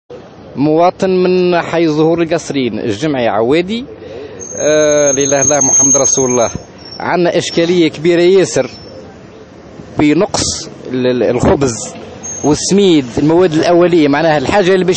Les habitants de Kasserine sont intervenus aujourd’hui au micro de Tunisie Numérique pour exprimer leur colère face à la pénurie de certains matières premières dont la semoule et la farine.